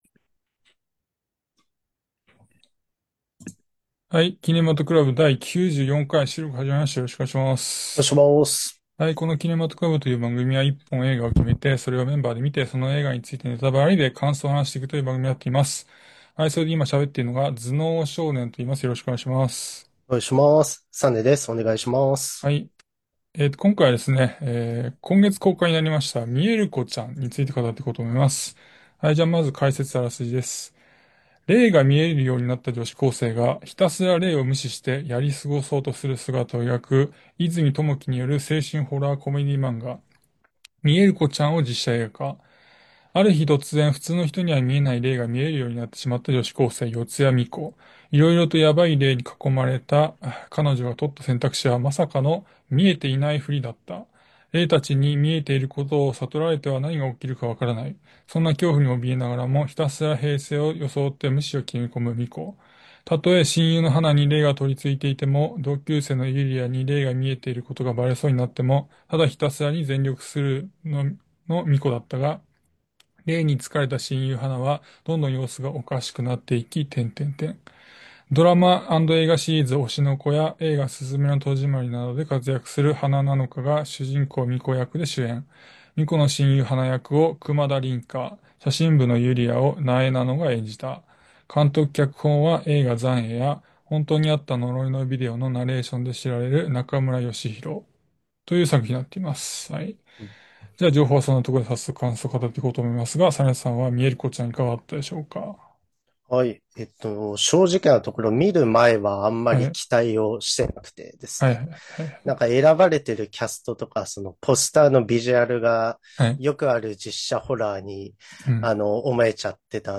映画好きの男達が毎回映画の課題作を決め、それを鑑賞後感想を話し合います。